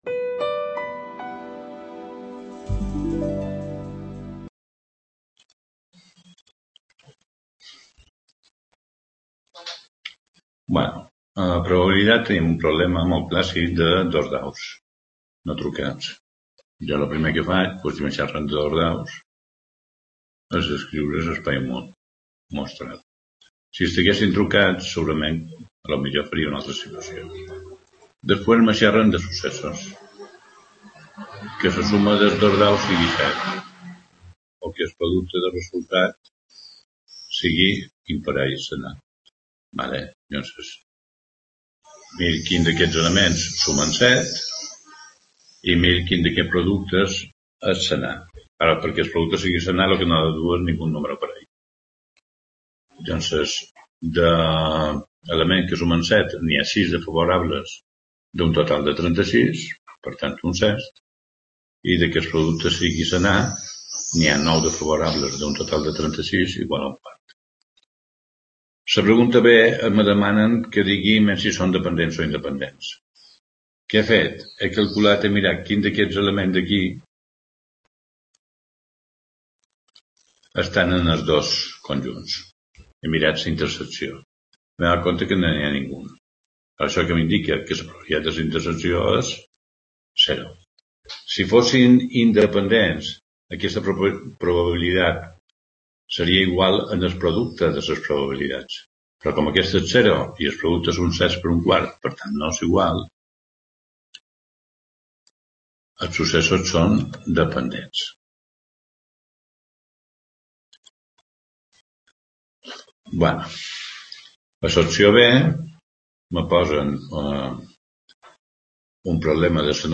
Explicacions
Video Clase